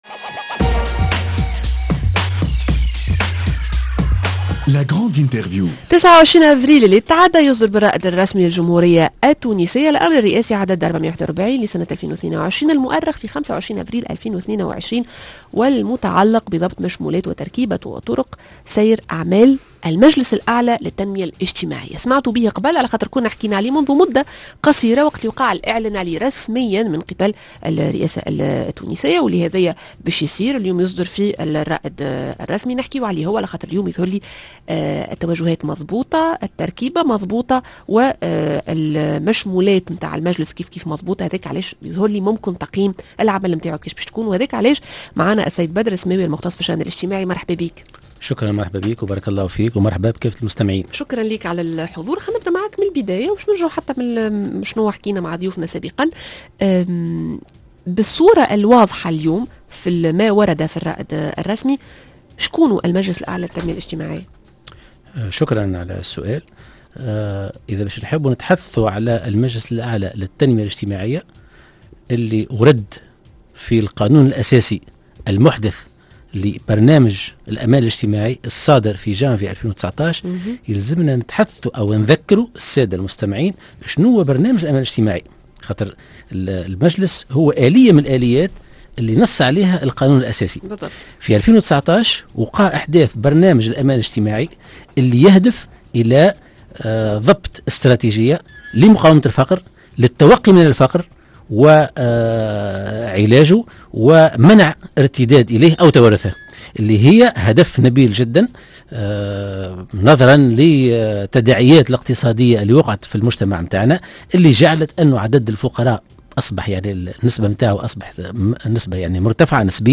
La grande interview: المجلس الأعلى للتنمية الإجتماعية يمهّد لتغيير منظومة الدعم؟